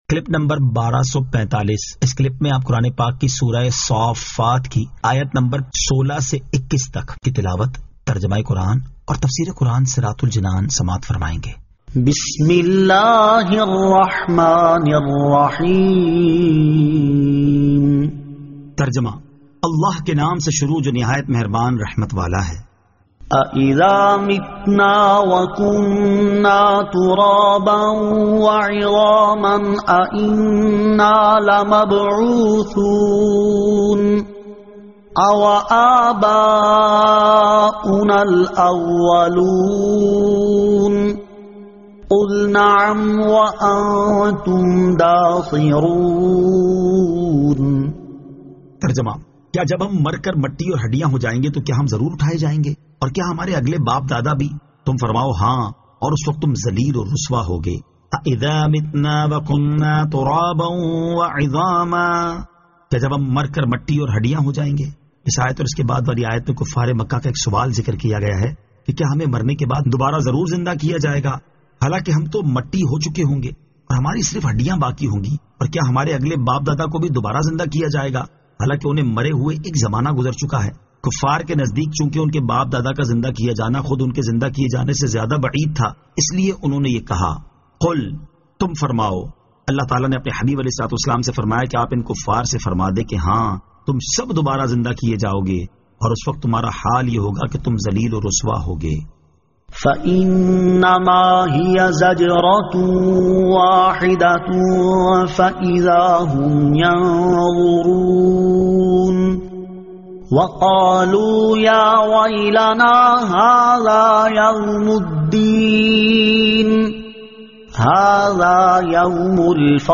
Surah As-Saaffat 16 To 21 Tilawat , Tarjama , Tafseer